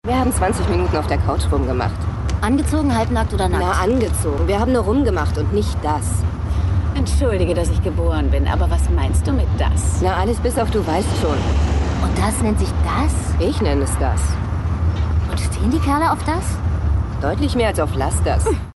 die Dialoge anscheinend auch.